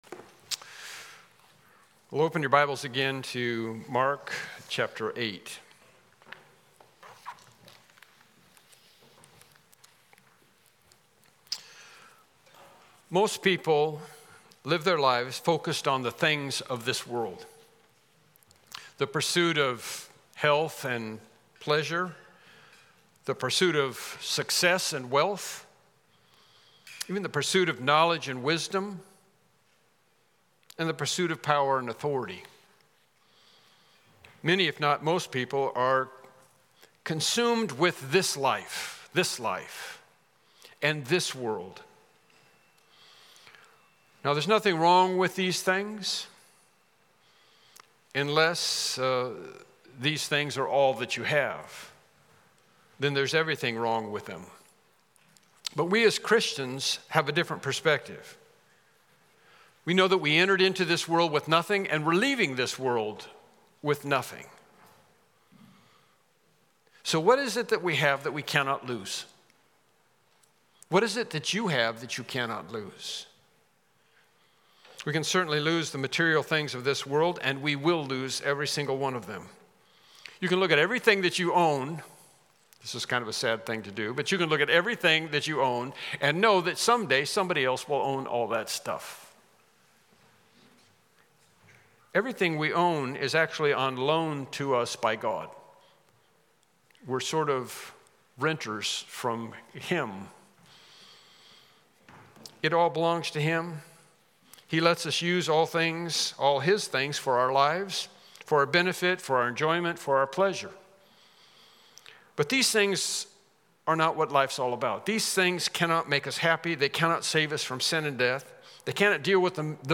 Mark Passage: Mark 8:35-38 Service Type: Morning Worship Service « Lesson 10